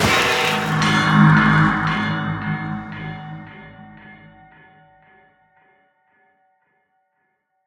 thunder1.ogg